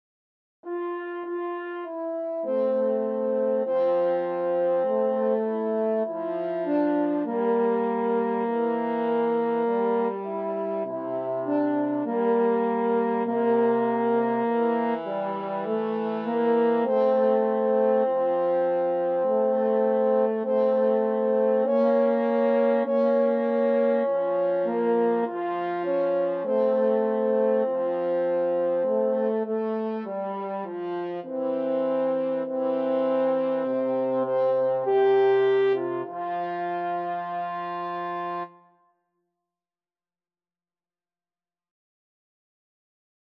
Espressivo
Arrangement for French Horn Duet
Classical (View more Classical French Horn Duet Music)